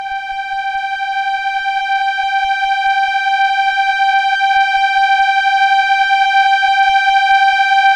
Index of /90_sSampleCDs/Keyboards of The 60's and 70's - CD1/STR_Elka Strings/STR_Elka Cellos
STR_ElkaVcG_6.wav